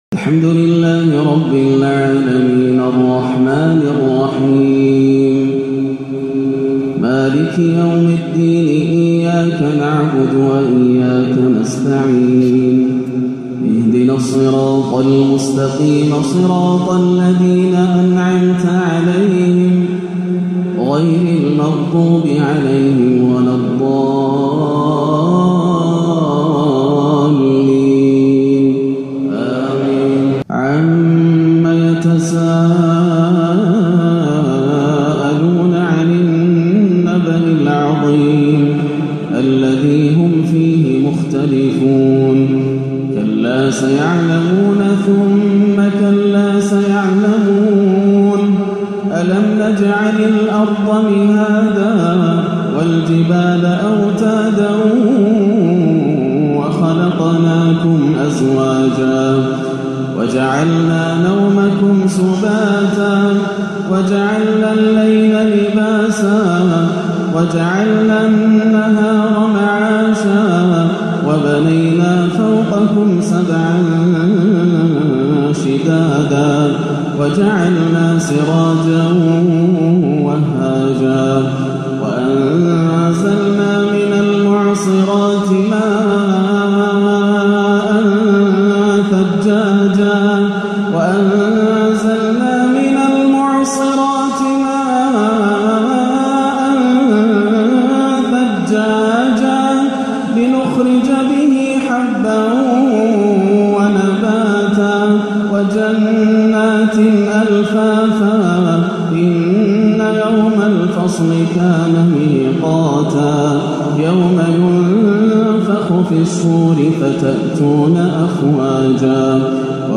(يَومَ يَقومُ الرُّوحُ وَالْمَلَائِكَةُ صفًّا) تلاوة حجازية رائعة لسورتي النبأ والأعلى - الأحد 12-5 > عام 1437 > الفروض - تلاوات ياسر الدوسري